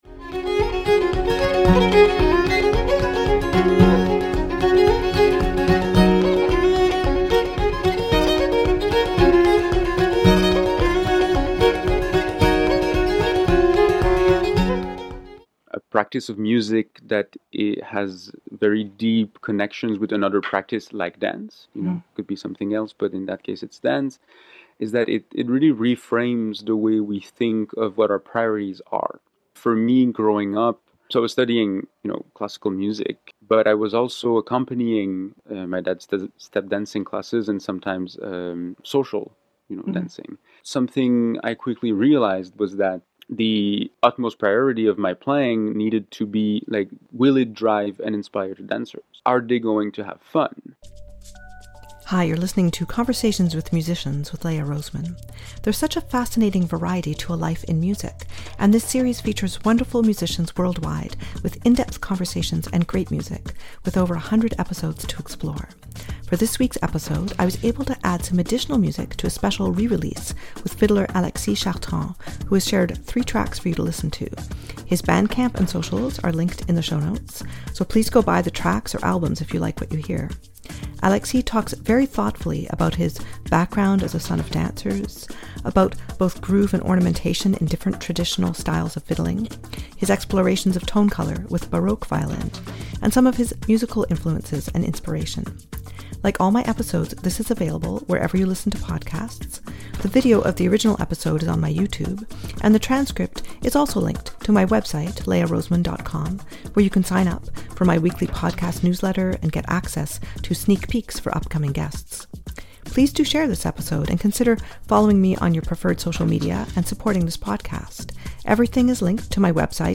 There’s such a fascinating variety to a life in music; and this series features wonderful musicians worldwide with in-depth conversations and great music, with over 100 episodes to explore.